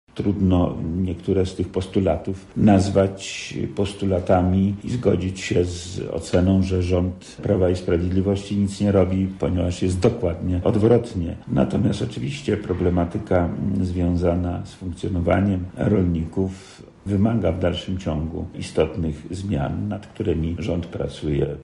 Rolnicy przygotowali list do rządzących, który dostarczyli do urzędu wojewódzkiego. Zawarte w nim punkty skomentował wojewoda lubelski Lech Sprawka: